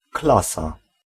Ääntäminen
Ääntäminen Tuntematon aksentti: IPA: /ˈklasa/ Haettu sana löytyi näillä lähdekielillä: puola Käännös Konteksti Ääninäyte Substantiivit 1. class ohjelmointi RP UK US Ireland all 2. classroom UK Suku: f .